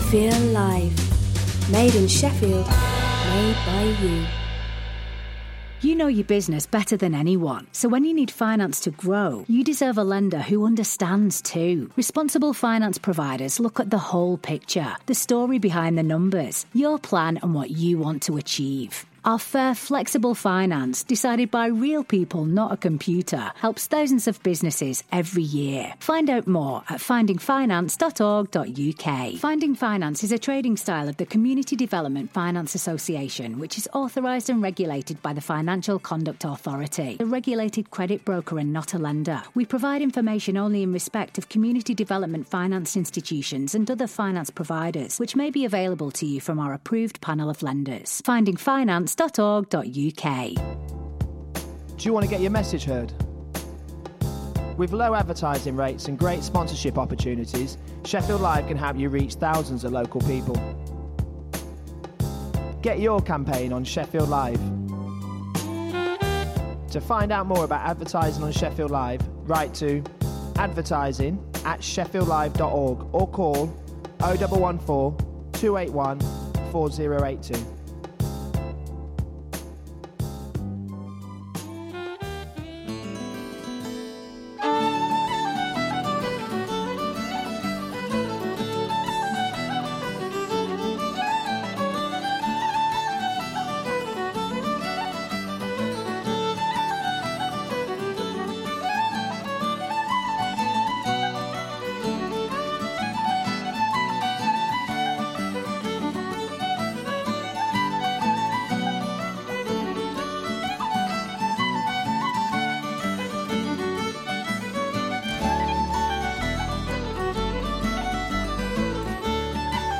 Write Radio is a two hour radio show which showcases new and local writing from the people of South Yorkshire.